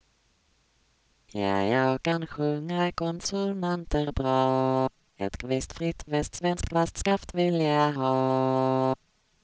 Burcas is a modest attempt at concatenated singing synthesis for Swedish. The system employs the MBROLA speech generator and a prerecorded diphone data base.
consonant transitions sound fine, whereas
konsonanter.wav